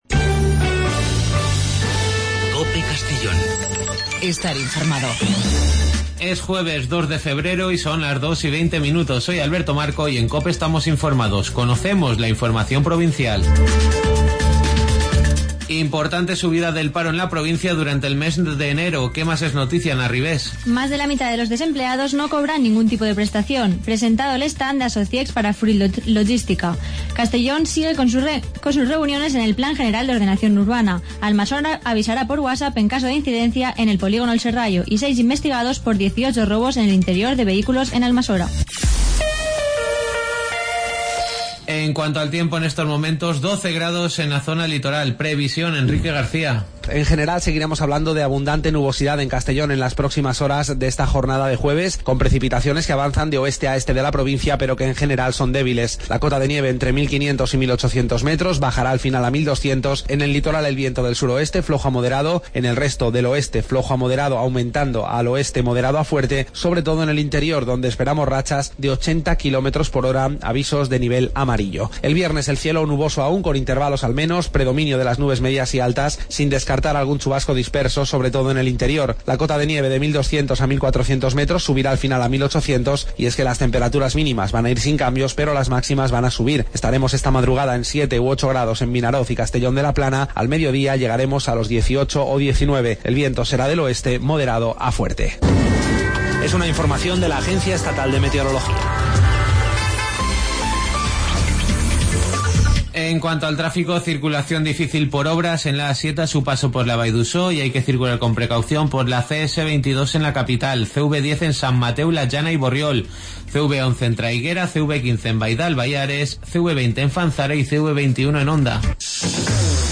Las noticias del día de 14:20 a 14:30 en Informativo Mediodía COPE en Castellón.